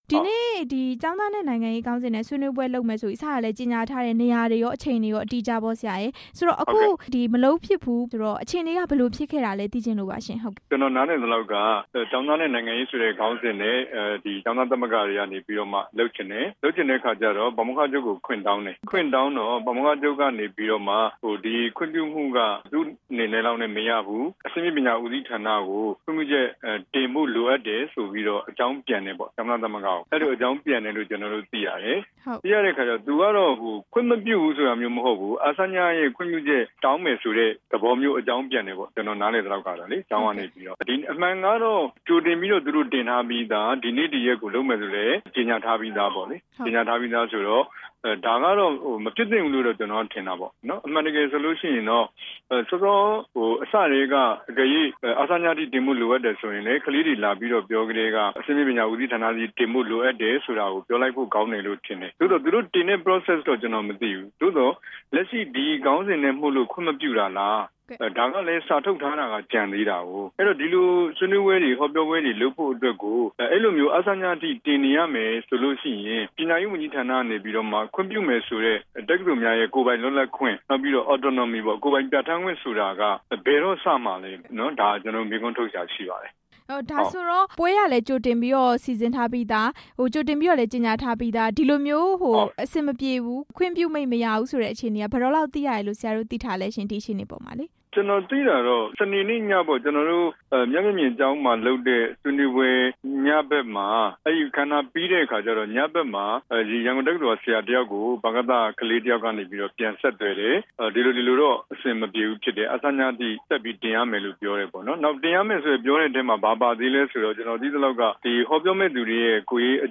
ဆွေးနွေးပွဲ ရက်ရွှေ့ဆိုင်းတဲ့ အကြောင်း မေးမြန်းချက်